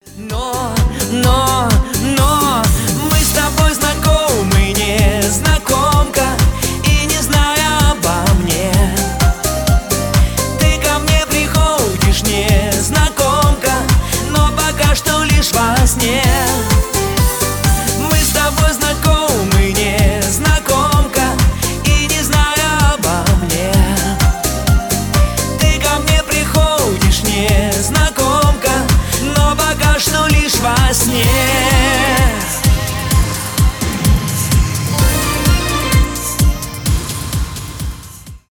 ретро
поп , эстрадные